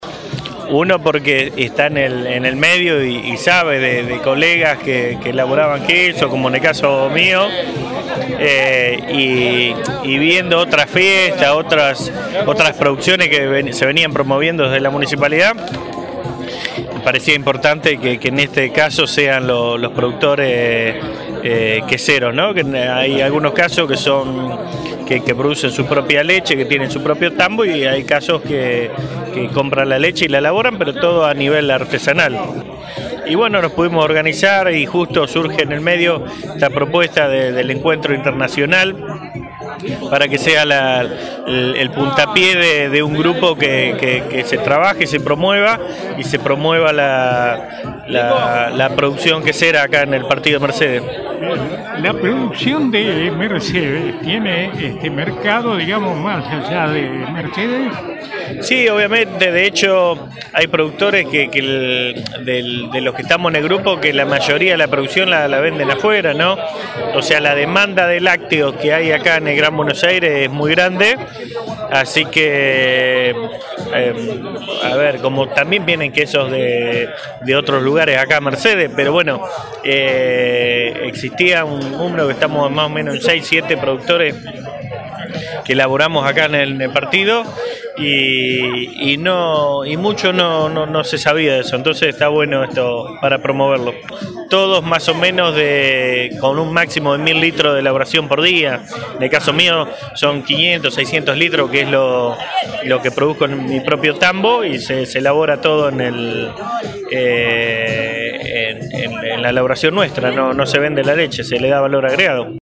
EN RADIO UNIVERSO